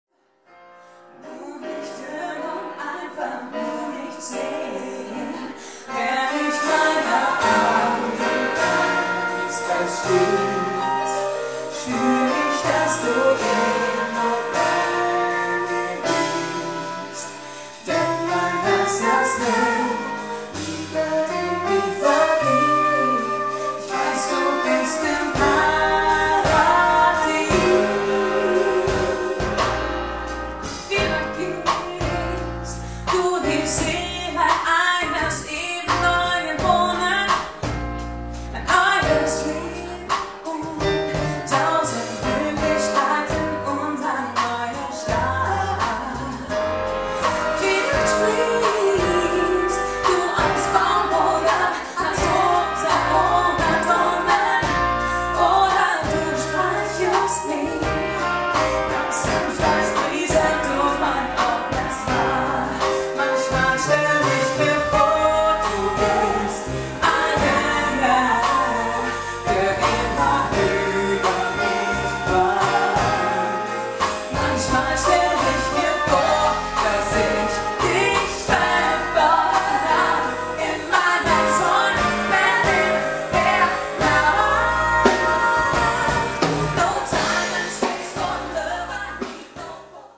Live-Mitschnitte: